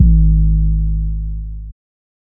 Metro 808_1.wav